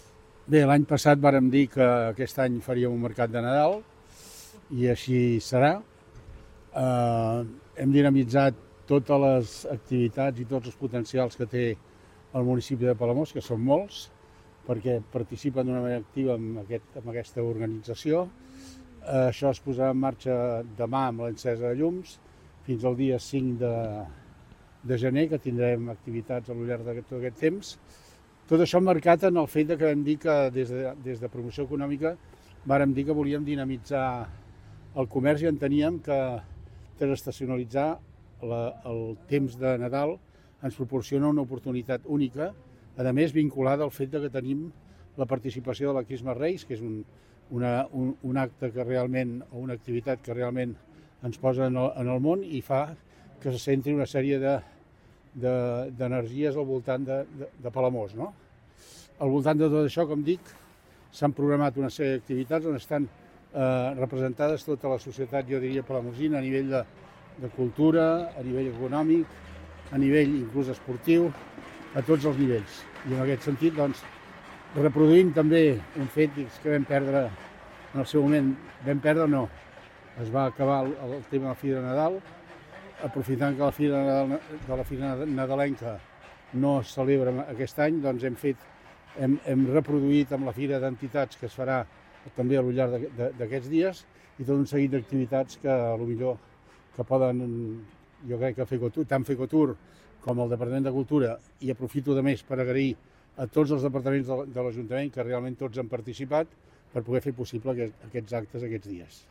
Ho explica el regidor de Promoció Econòmica de l’Ajuntament de Palamós, Antoni Bachiller.